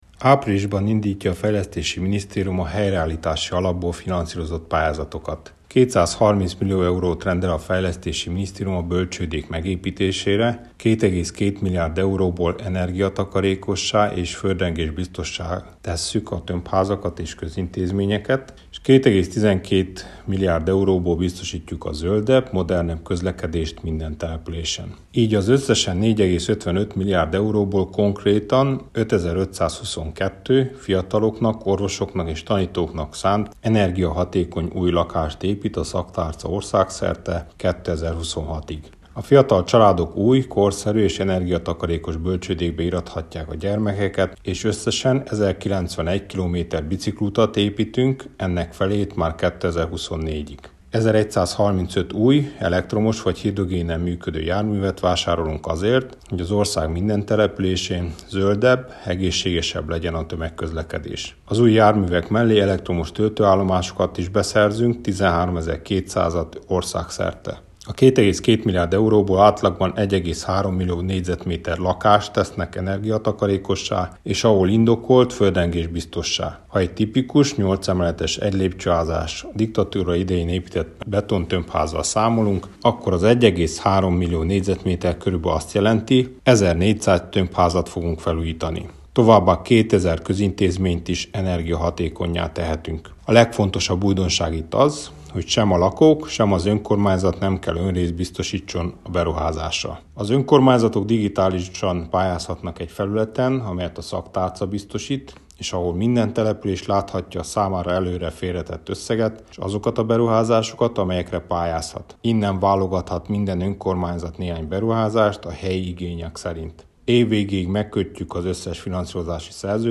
Cseke Attila fejlesztési és közigazgatási minisztert hallhatják.